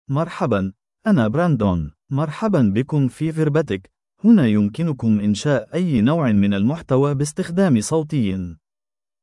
MaleArabic (Standard)
BrandonMale Arabic AI voice
Brandon is a male AI voice for Arabic (Standard).
Voice sample
Brandon delivers clear pronunciation with authentic Standard Arabic intonation, making your content sound professionally produced.